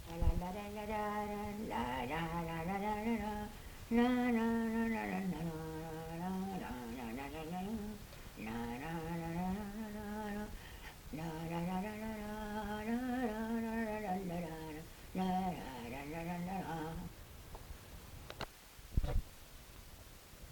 Mazurka (fredonné)
Lieu : Mas-Cabardès
Genre : chant
Effectif : 1
Type de voix : voix de femme
Production du son : fredonné
Danse : mazurka